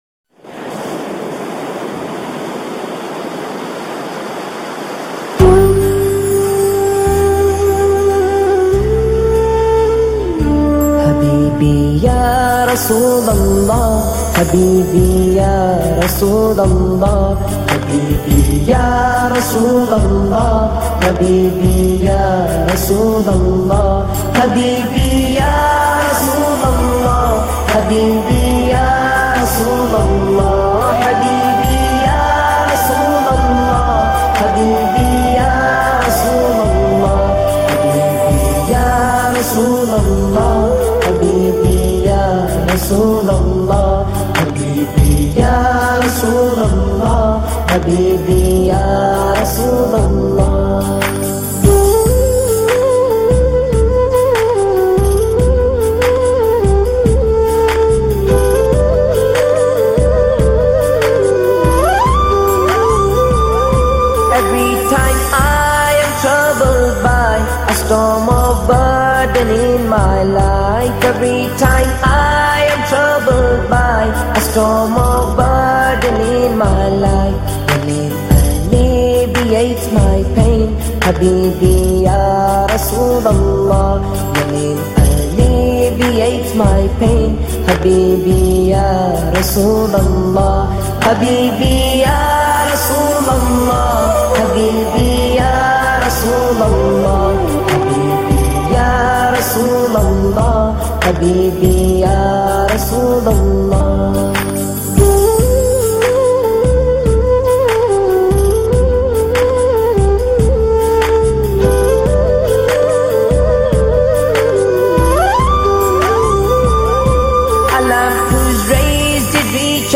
Islamic Qawwalies And Naats